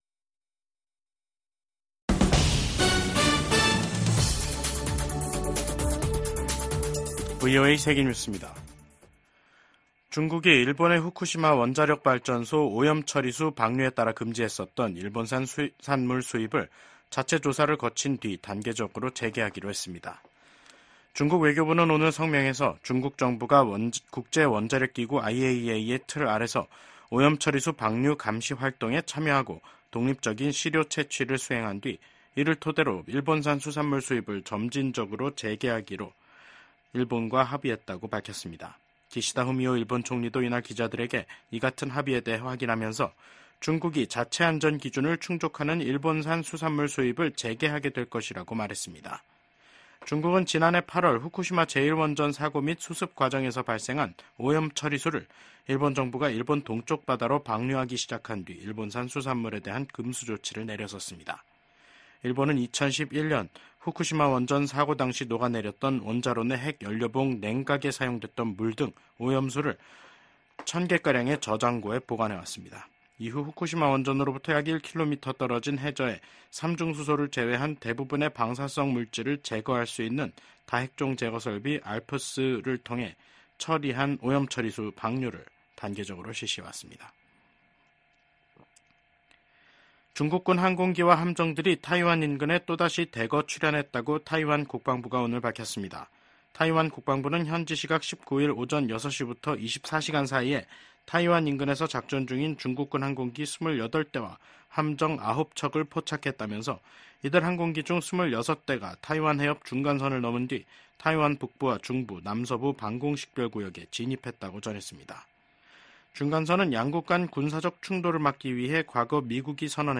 VOA 한국어 간판 뉴스 프로그램 '뉴스 투데이', 2024년 9월 20일 2부 방송입니다. 미국 정부가 북-러 간 불법 자금 거래에 관여한 러시아 회사 5곳 및 국적자 1명을 전격 제재했습니다.